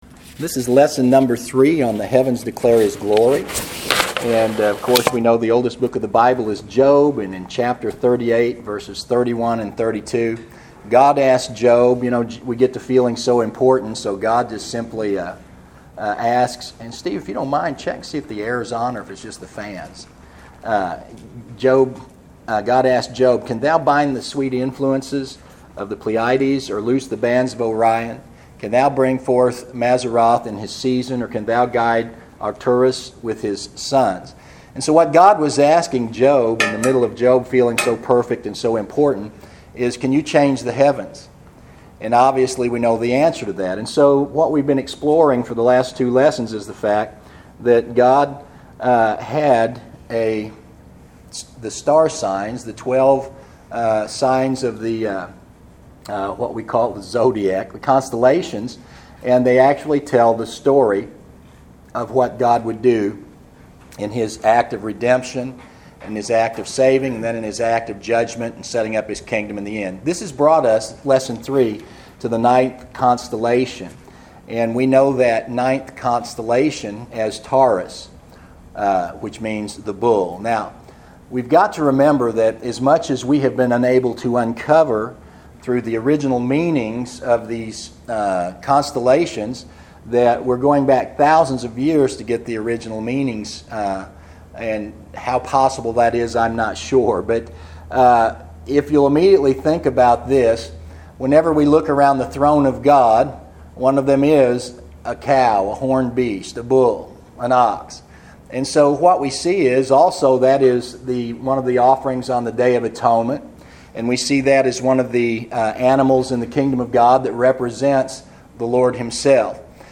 This is the concluding teaching on the Bible written in the stars. Here we cover the last of the twelve constellations and their scriptural meaning.